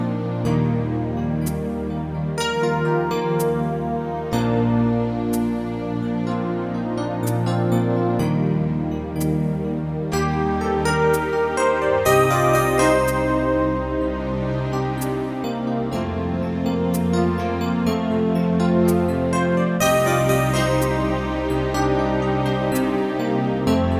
End Cut Pop (1980s) 3:57 Buy £1.50